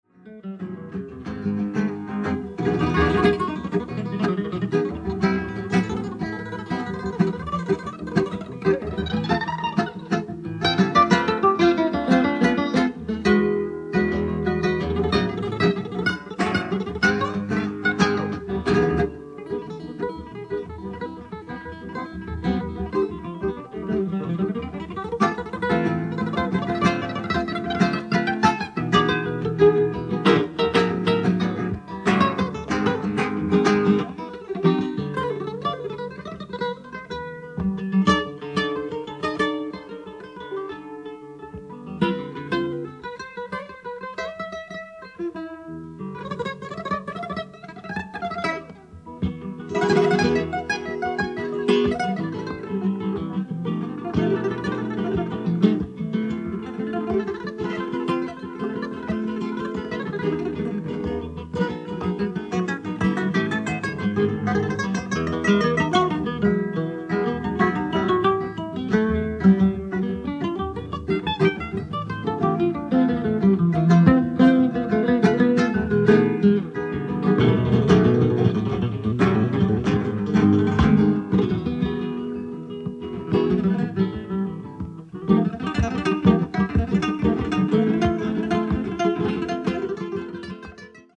ライブ・アット・ドイチュ・シャウシュピールハウス、ハンブルグ、ドイツ
※試聴用に実際より音質を落としています。
guitar